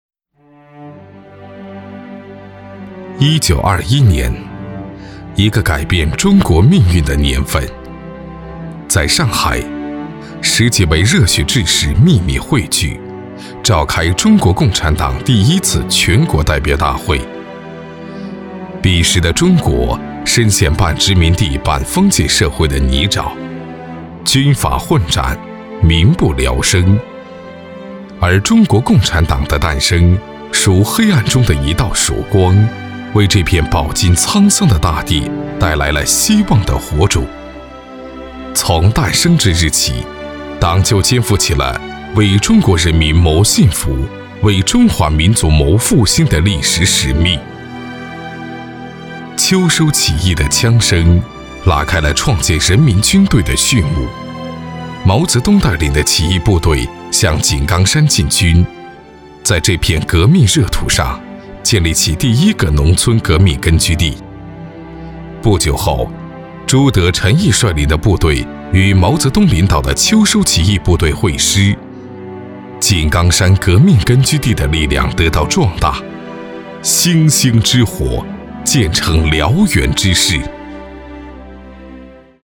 男国语217